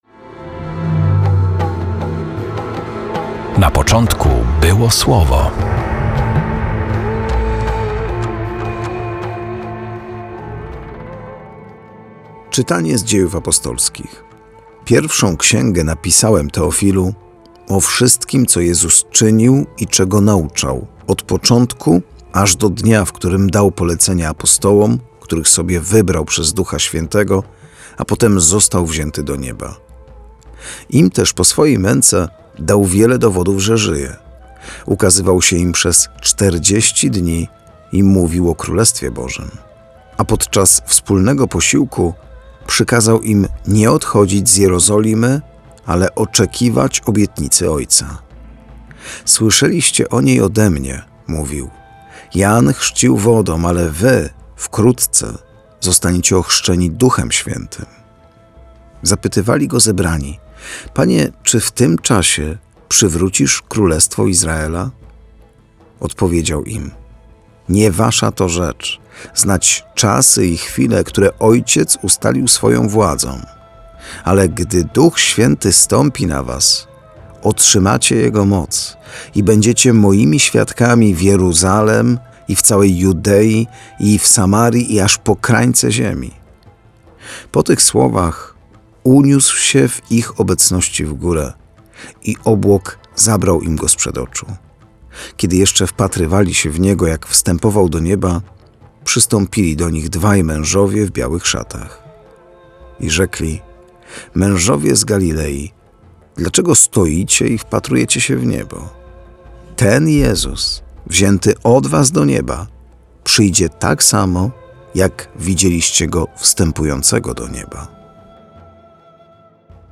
Perły ukryte w liturgii słowa odkrywają księża